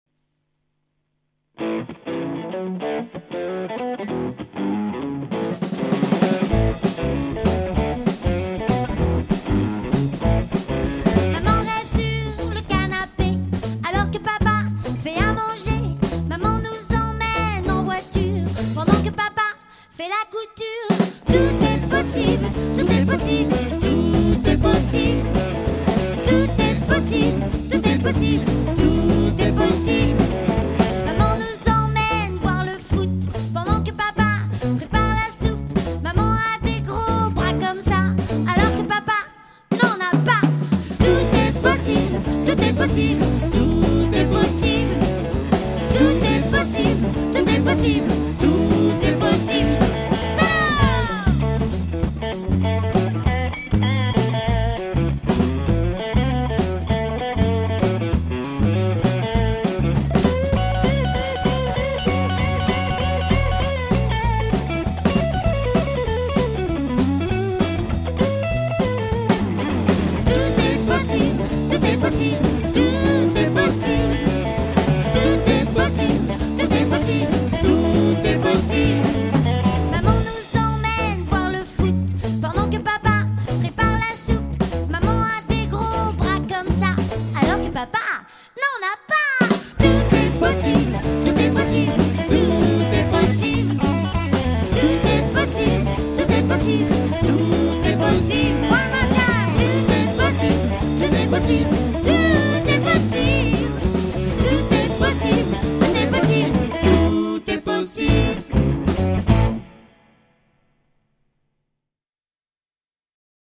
Chant
Basse
Guitare
Batterie
Saxophone soprano